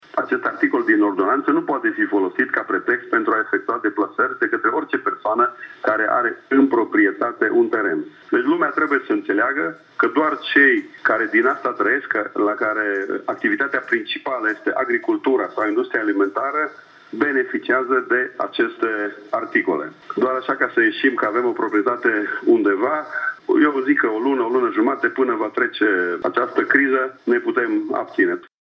Pe de altă parte, ministrul Agriculturii, Adrian Oros, precizează că doar producătorii agricoli au acest drept: